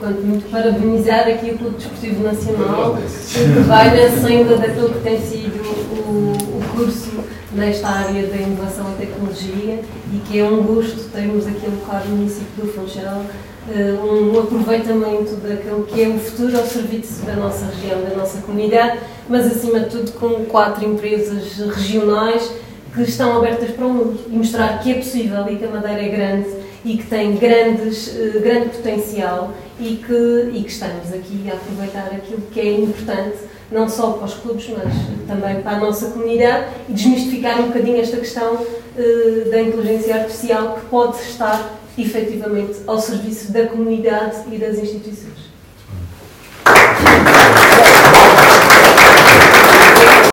ÁUDIOS | discursos:
Dr.ª Helena Leal – Vereadora da Câmara Municipal do Funchal: